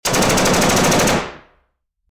Download Free Gun Shot Sound Effects
Gun Shot